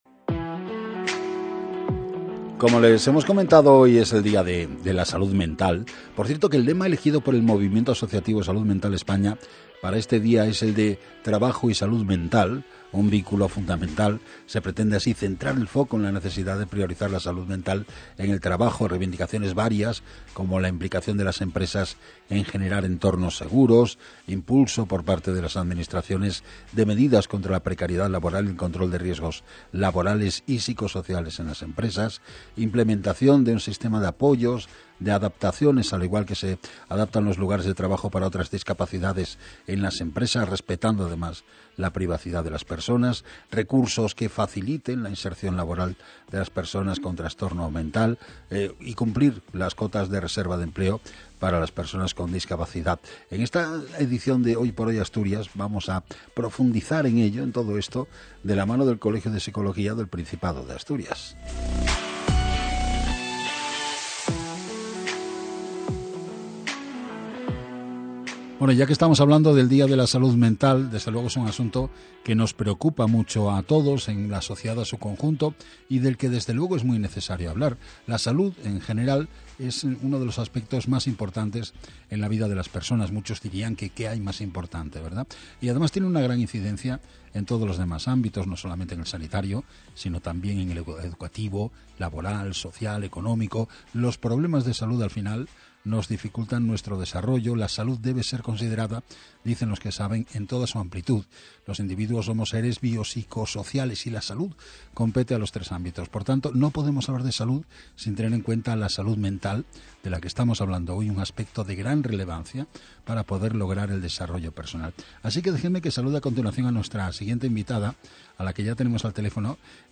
Entrevista-radio-dia-de-la-salud-mental.mp3